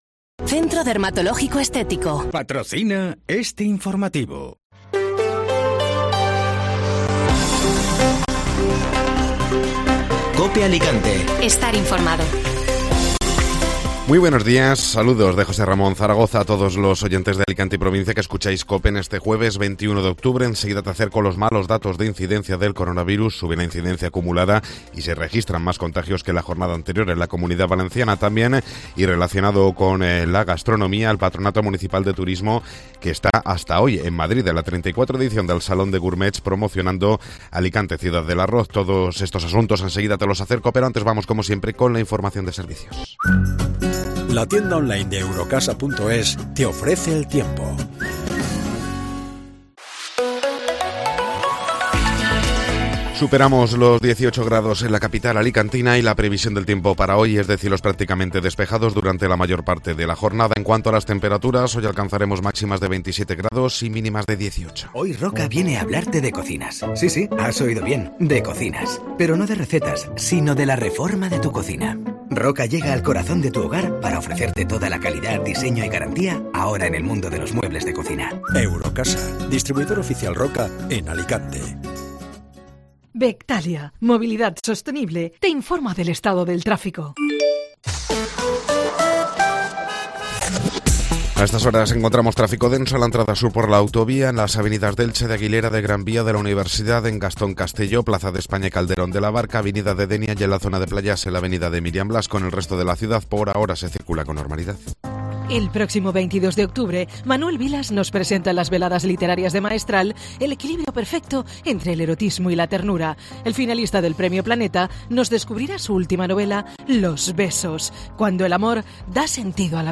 Informativo Matinal (Jueves 21 de Octubre)